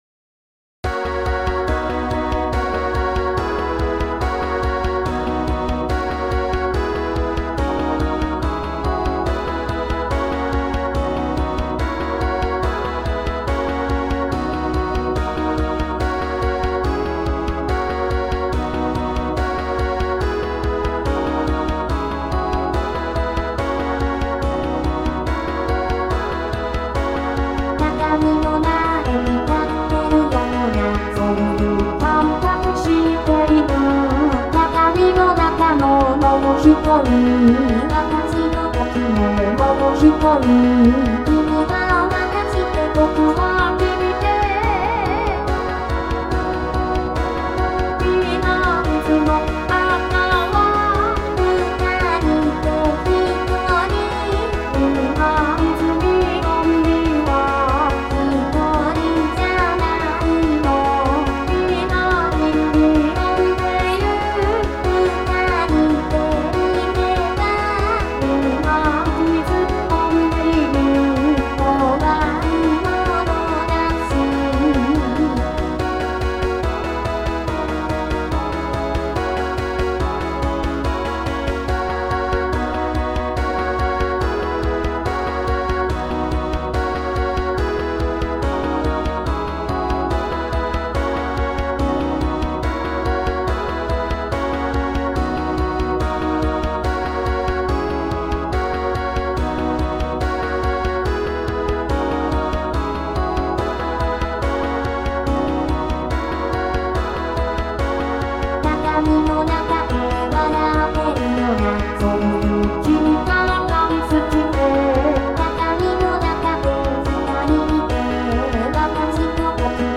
響いてる感も出したかったです。